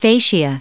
Prononciation du mot fascia en anglais (fichier audio)
Prononciation du mot : fascia